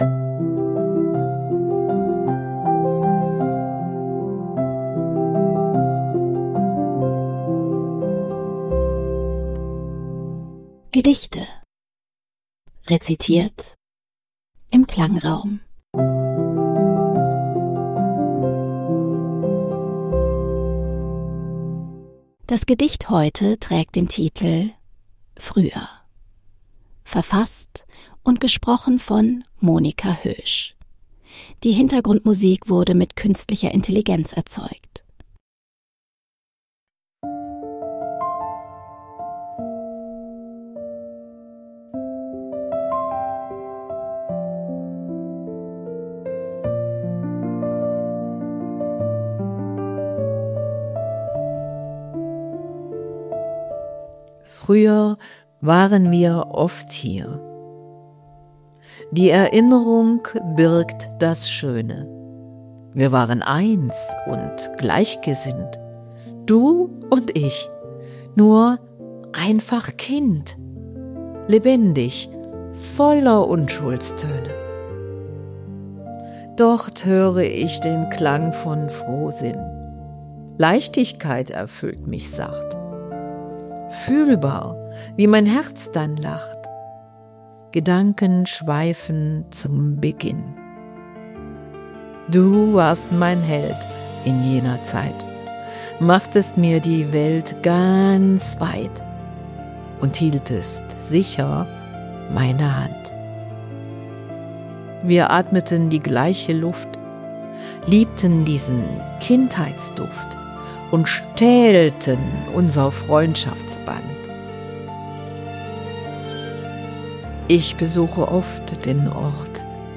Klangraumgedicht "Früher"